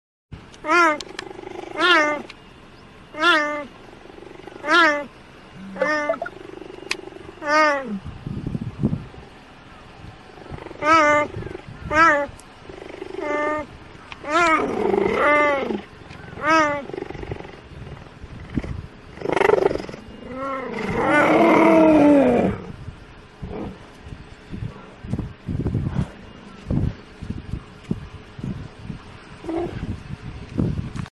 На этой странице собраны звуки гепарда в естественной среде обитания: от грозного рыка до нежного мурлыканья детенышей.
Звуки гепарда: какие звуки издает гепард, когда мяукает